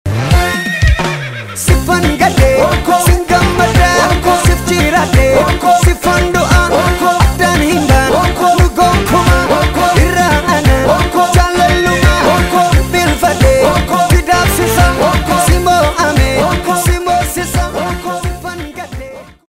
OROMO MUSIC